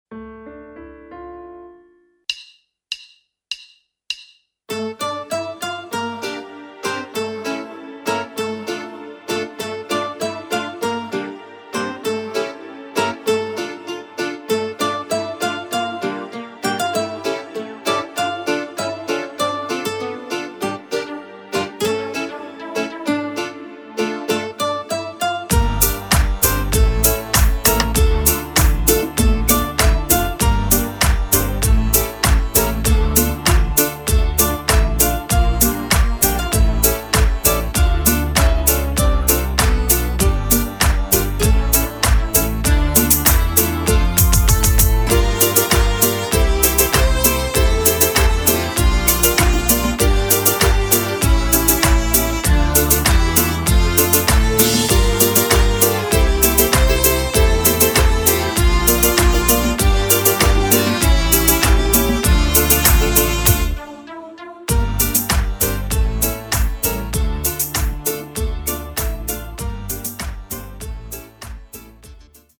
DEMO pesničky pre Vás vo formáte mp3  na stiahnutie.
(instr)